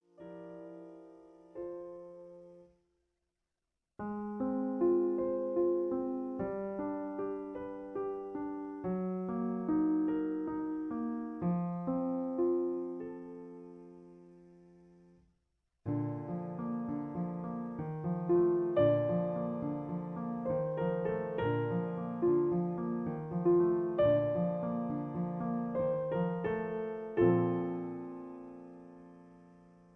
In B flat. Piano Accompaniment